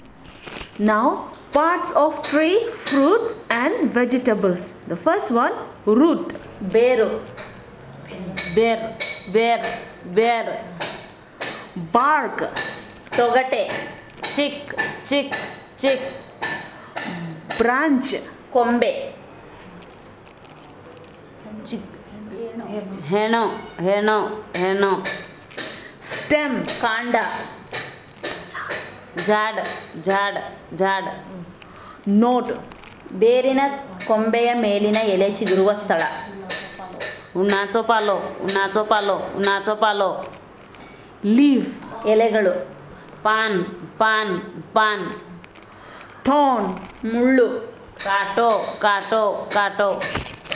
Elicitation of words about trees, fruits, and vegetables